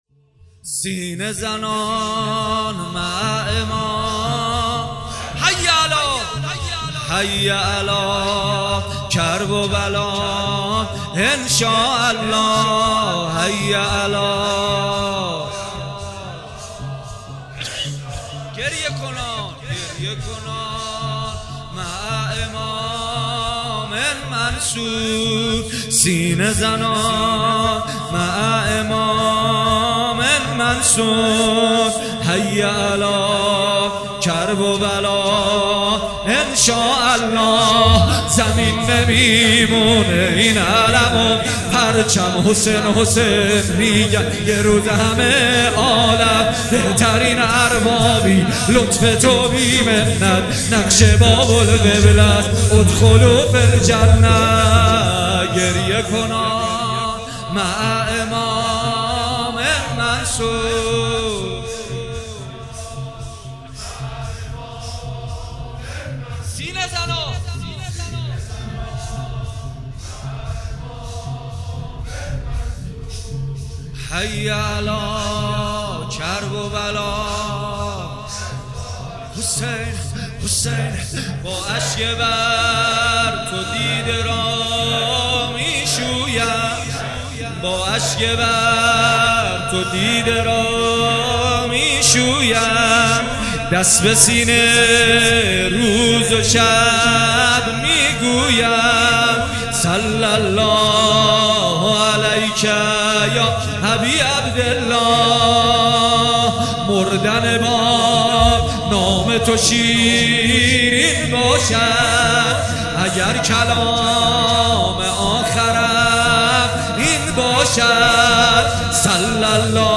مداحی جدید کربلایی محمدحسین پویانفر شب اول محرم 1399 هیات ریحانة النبی(س) تهران